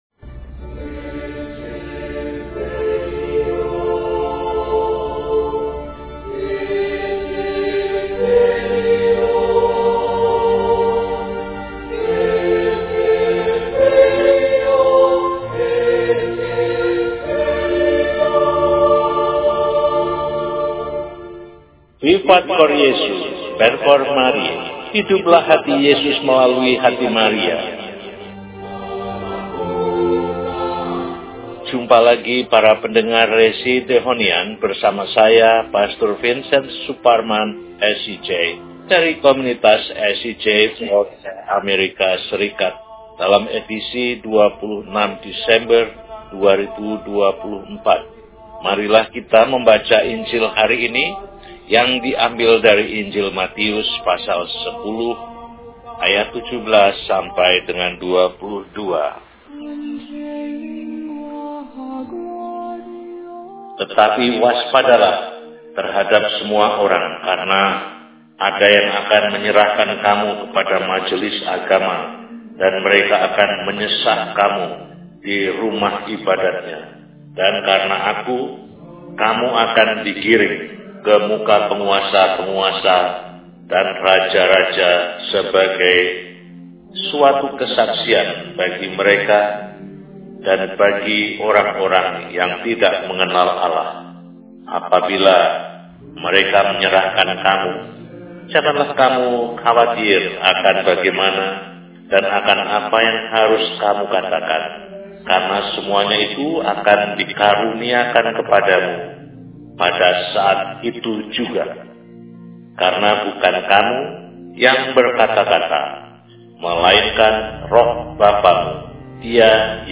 Kamis, 26 Desember 2024 – Pesta St. Stefanus, Martir Pertama – RESI (Renungan Singkat) DEHONIAN